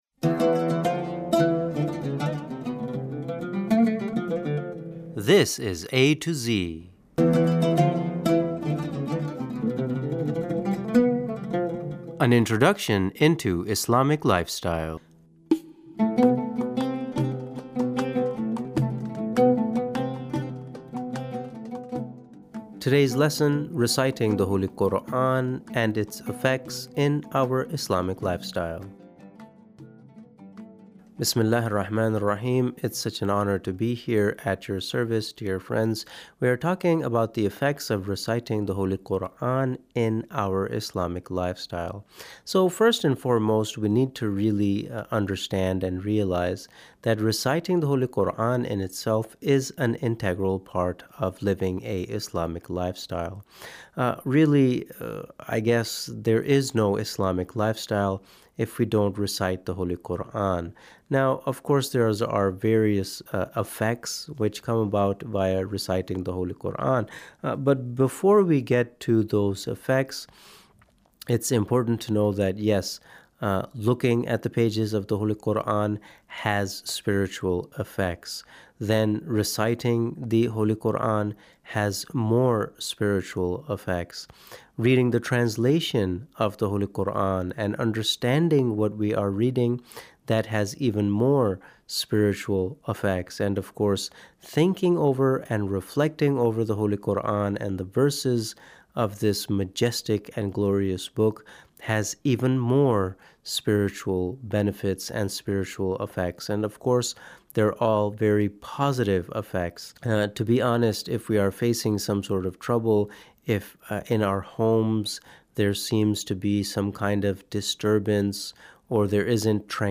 Reciting the holy Quran